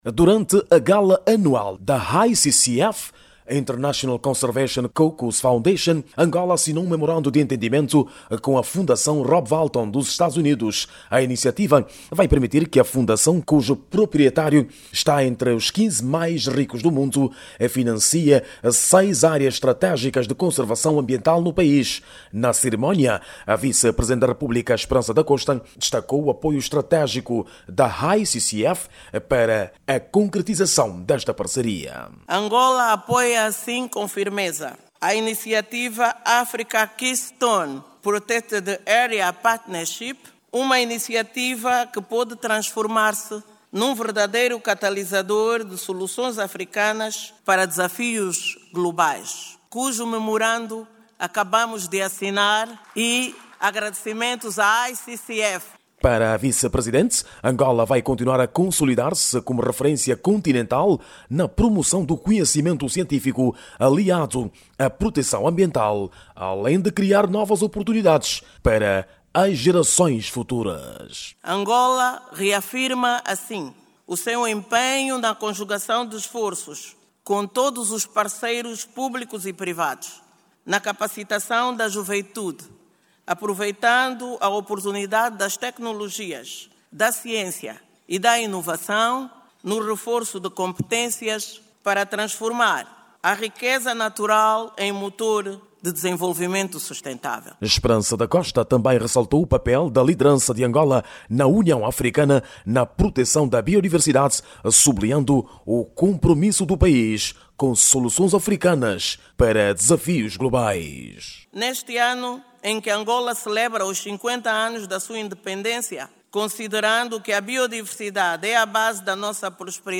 a partir de Washington DC: